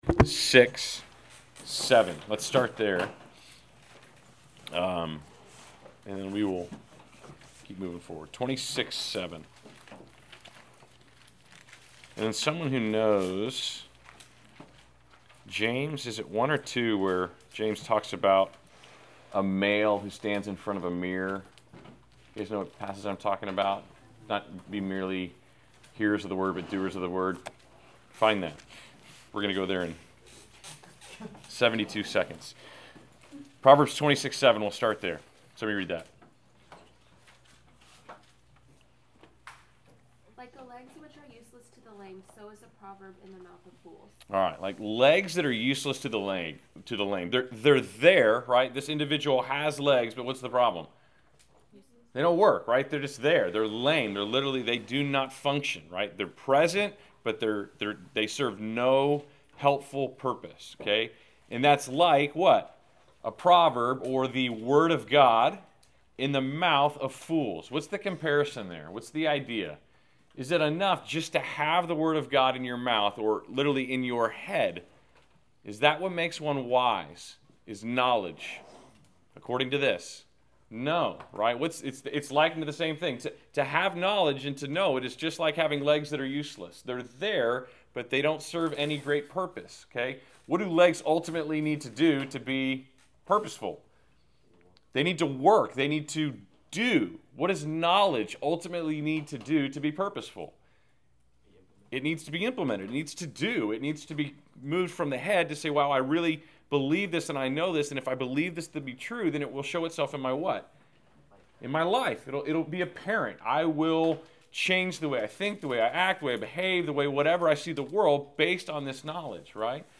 Class Session Audio October 29